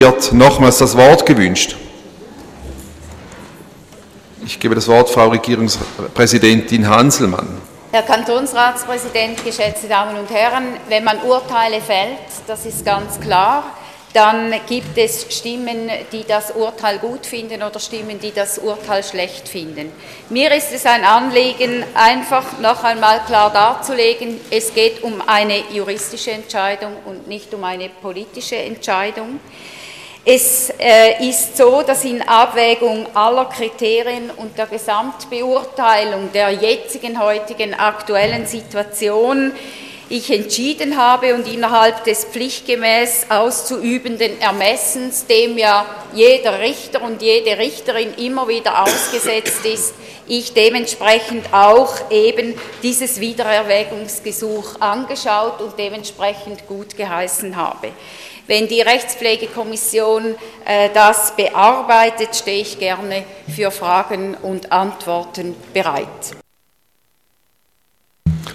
Session des Kantonsrates vom 24. bis 26. November 2014